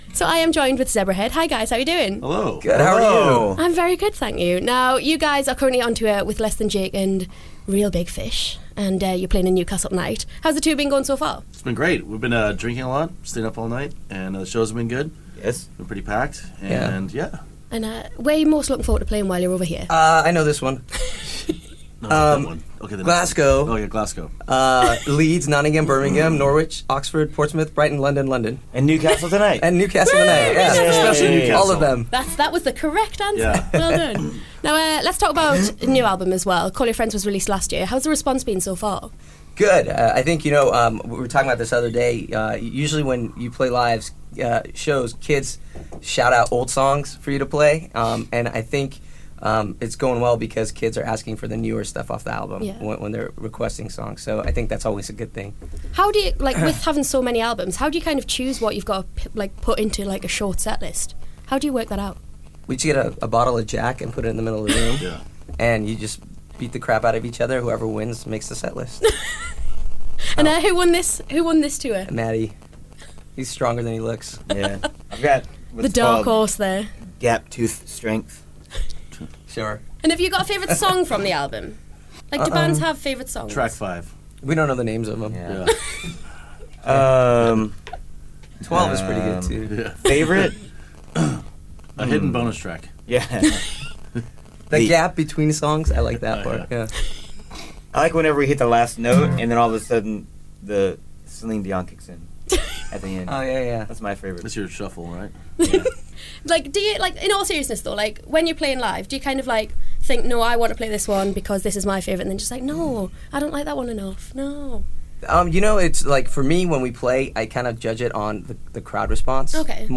They also played a couple of acoustic songs for us